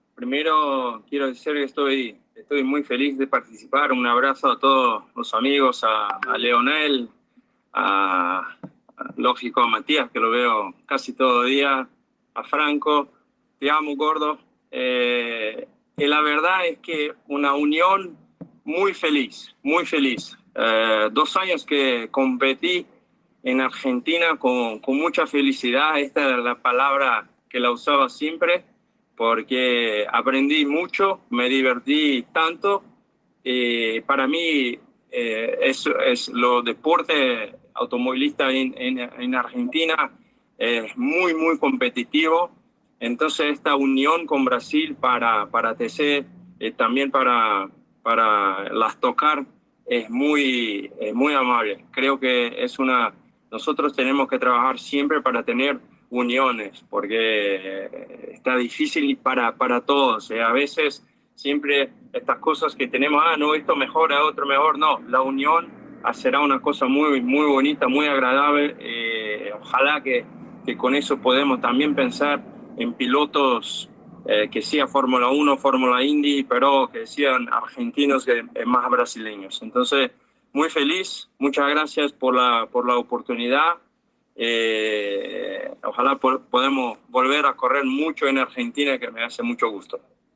El piloto brasilero estuvo presente en la conferencia de prensa y manifestó su alegría de volver al país para compartir fecha junto al TC2000 en los 200 km de Buenos Aires.
Rubens-Barrichello-Presentacion-ACA.mp3